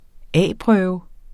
Udtale [ ˈæˀˌpʁœːwə ]